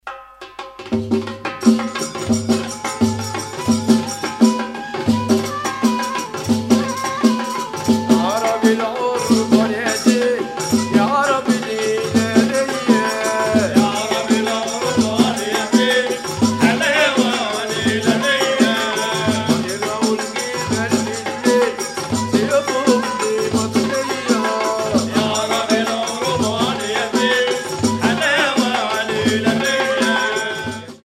Zar music is raw and unpolished.